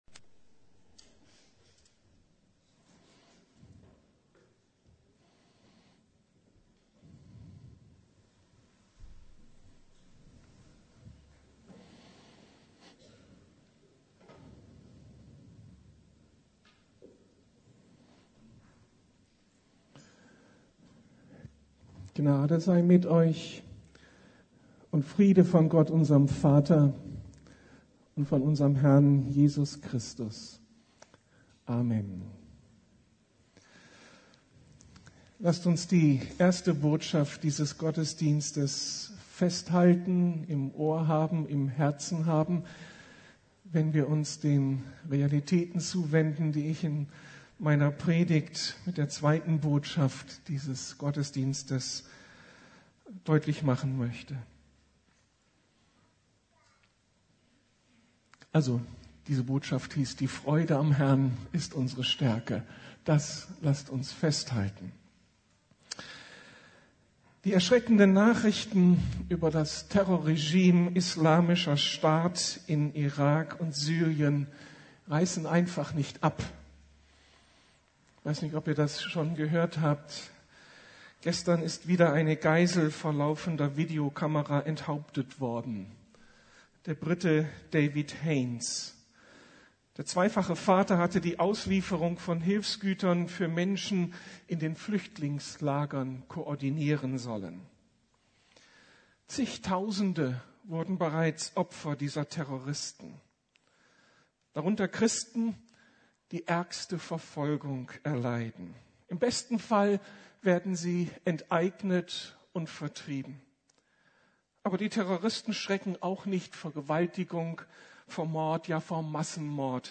Hilfe, wird mein Glaube ausreichen! ~ Predigten der LUKAS GEMEINDE Podcast